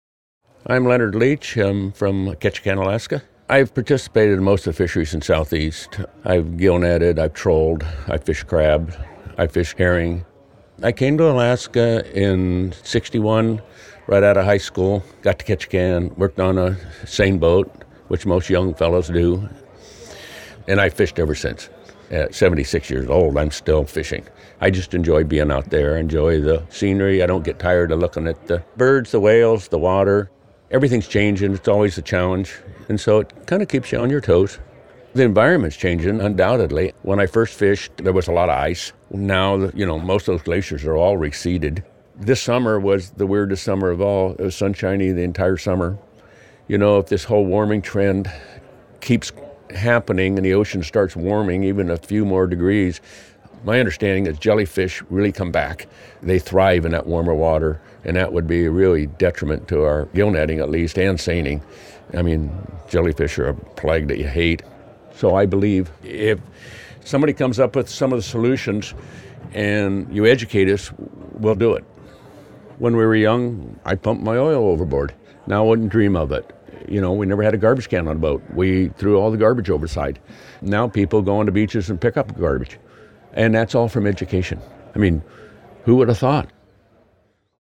These Tidal Change interviews were recorded in our soundproof studio booth at the Pacific Marine Expo trade show in Seattle, Washington, in November, 2018.